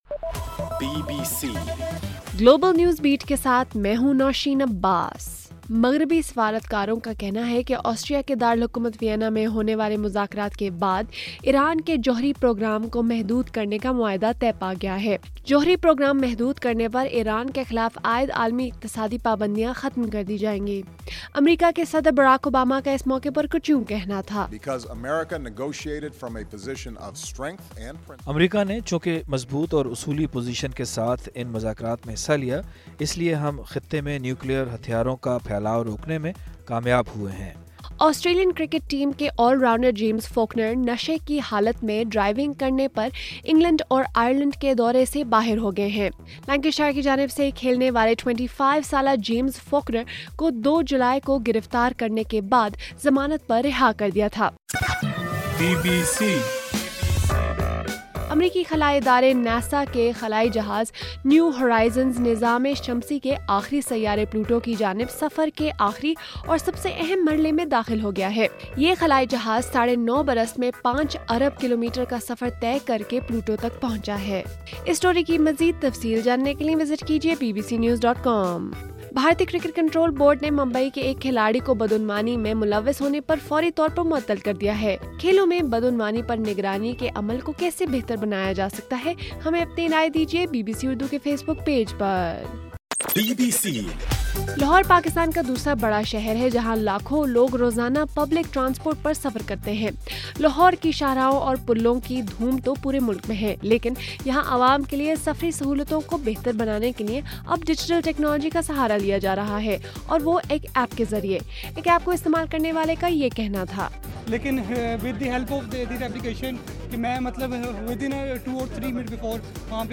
جولائی 14: رات 12 بجے کا گلوبل نیوز بیٹ بُلیٹن